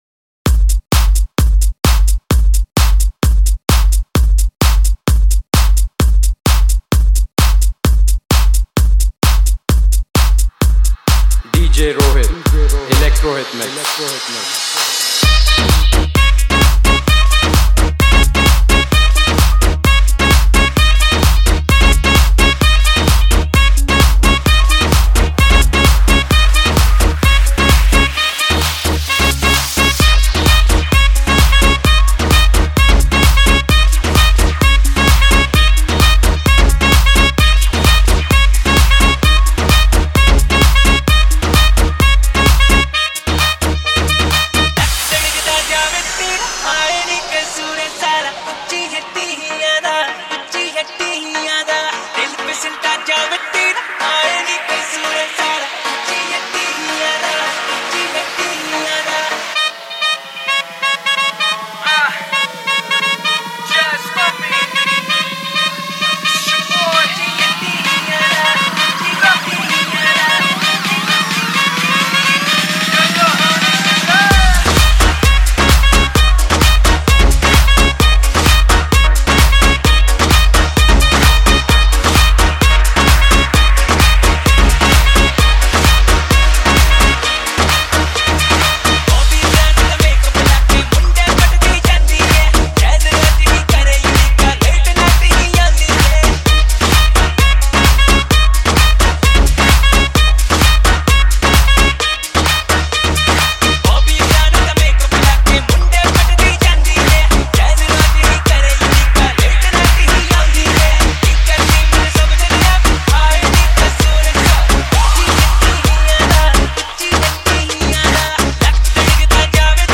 HomeMp3 Audio Songs > Others > Latest DJ-Mixes (March 2013)